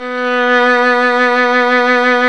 Index of /90_sSampleCDs/Roland - String Master Series/STR_Violin 2&3vb/STR_Vln2 _ marc
STR  VL B 4.wav